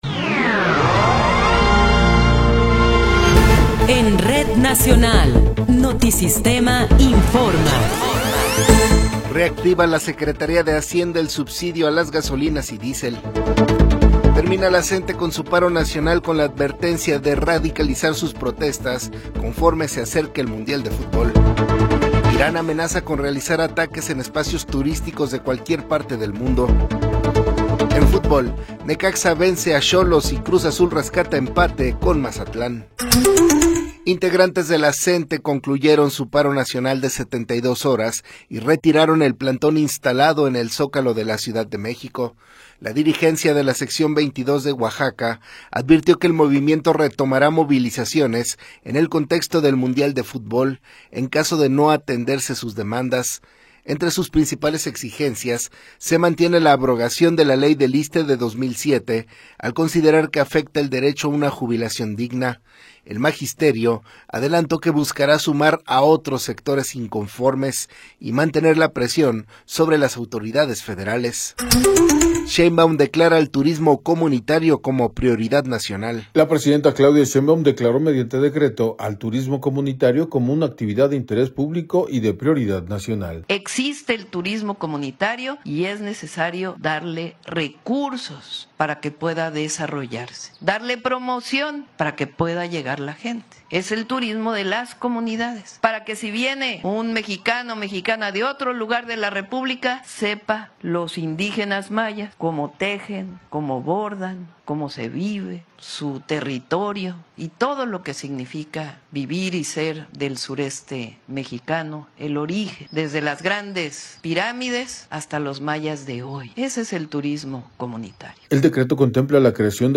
Noticiero 8 hrs. – 21 de Marzo de 2026
Resumen informativo Notisistema, la mejor y más completa información cada hora en la hora.